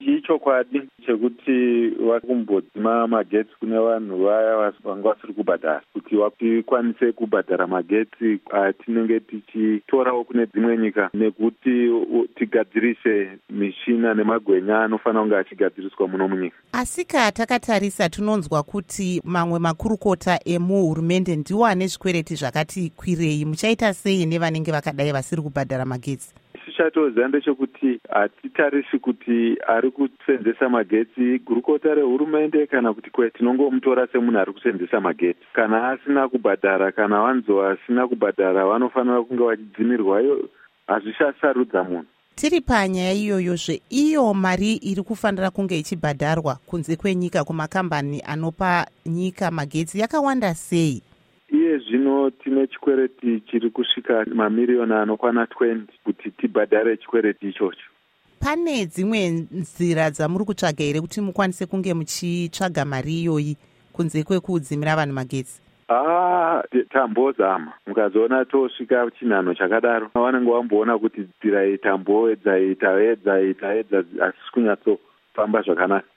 Hurukuro NaVa Elton Mangoma